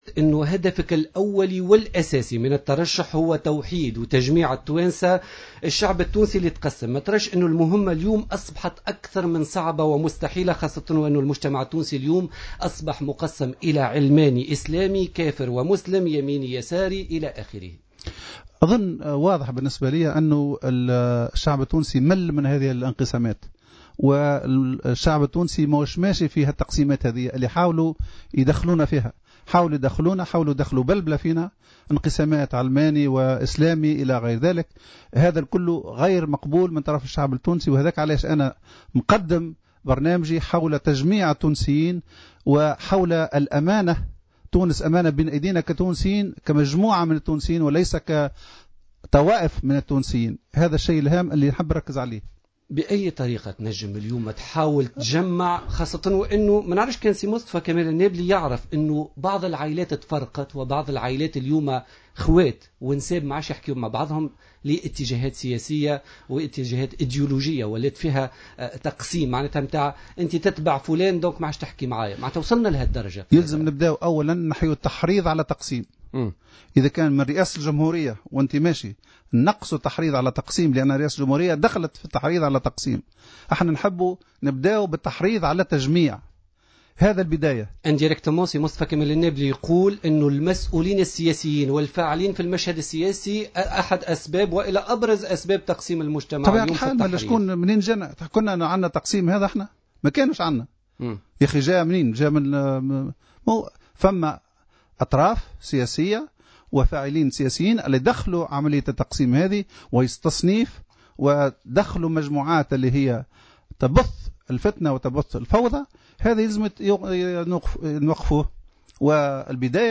أوضح مصطفى كمال النابلي المترشح للانتخابات الرئاسية المقبلة في مداخلة له في برنامج "بوليتيكا" أنه وبالرغم من علاقة التصاهر التي تجمعه بكمال اللطيف إلا أن ذلك ليس له أية علاقة بترشحه للرئاسية.